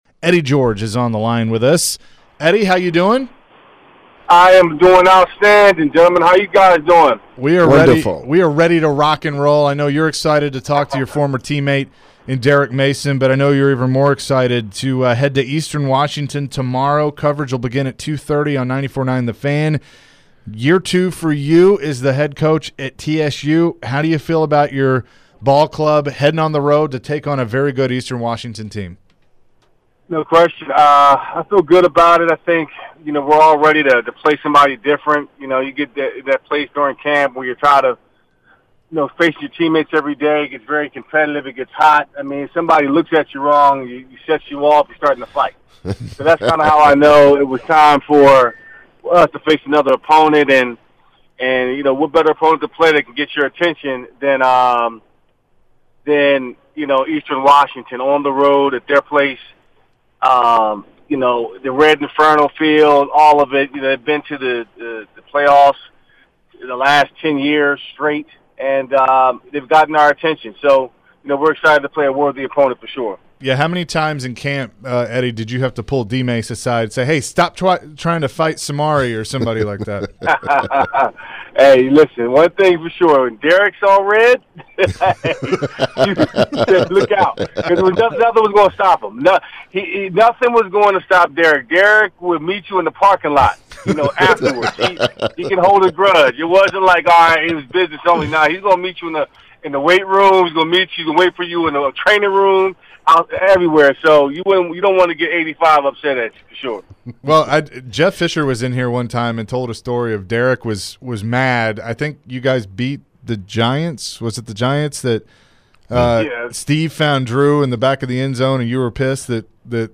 Eddie George interview (9-2-22)